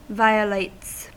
Ääntäminen
Ääntäminen US Haettu sana löytyi näillä lähdekielillä: englanti Violates on sanan violate yksikön kolmannen persoonan indikatiivin preesens.